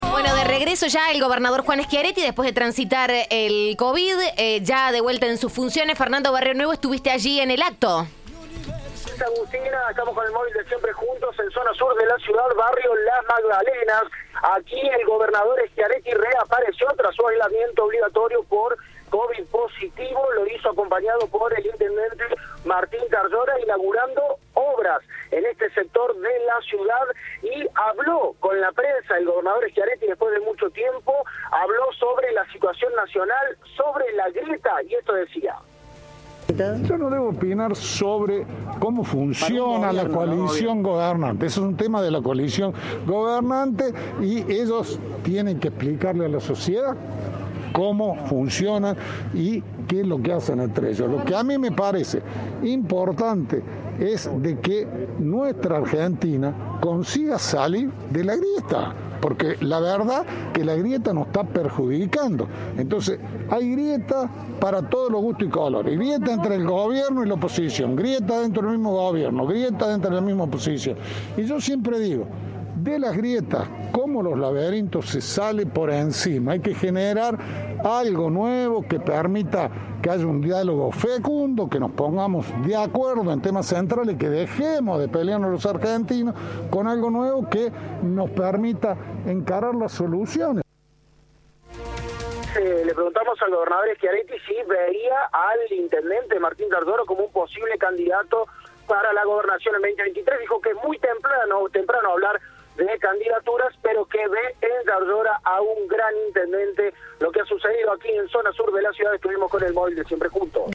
En un breve contacto con la prensa, Schiaretti evitó opinar puntualmente sobre las internas que sacuden al Frente de Todos: "Es un tema de ellos, quienes tienen que explicarle a la sociedad qué es lo que hacen".
Informe